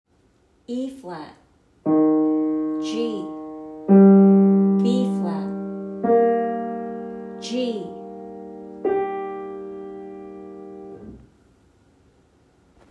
Refer back to Example 10.1.2. Because the clarinet in B-flat is a transposing instrument, the clarinet’s A does not sound like A: it sounds like G. This time, compare this G to the notes in the cello and piano: E-flat, G, and B-flat. The clarinet’s G now fits in with the other instruments.